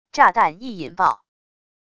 炸弹一引爆wav音频